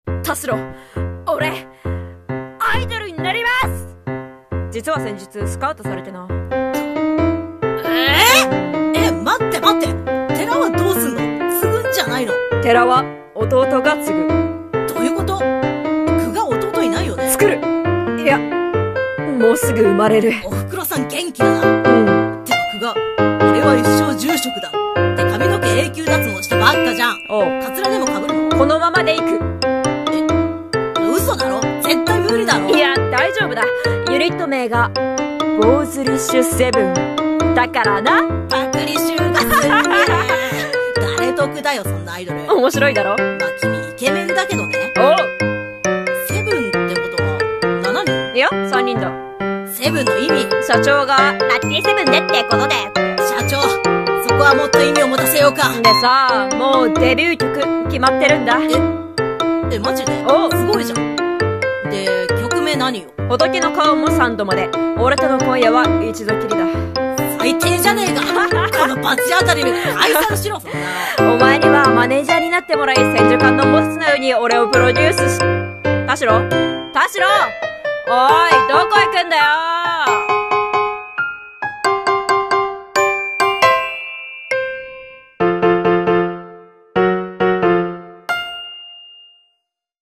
【ギャグ声劇】俺、アイドルになります！【掛け合い】